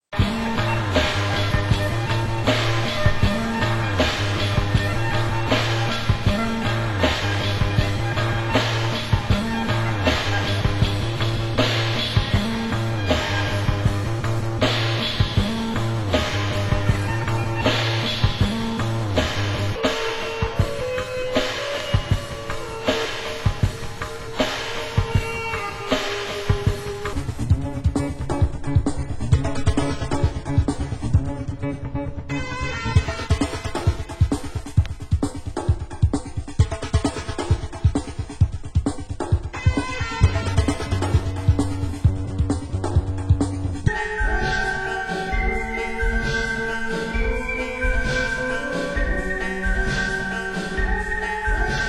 Genre: Leftfield